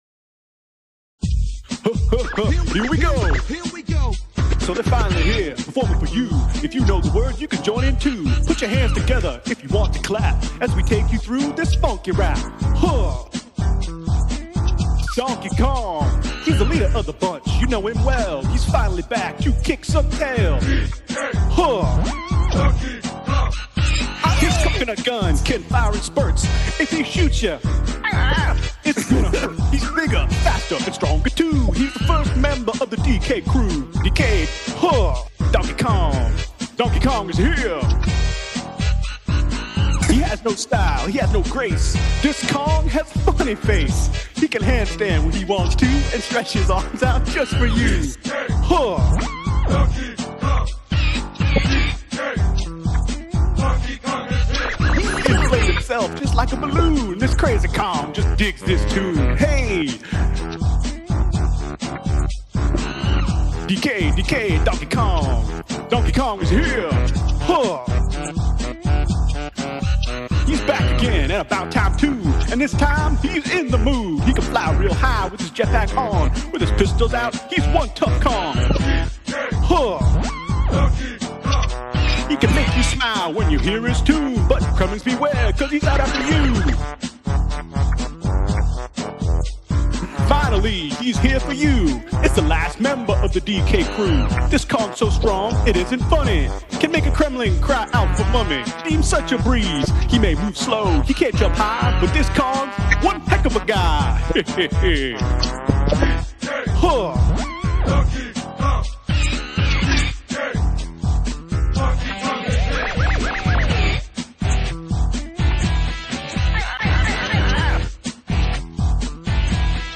While he was reading them.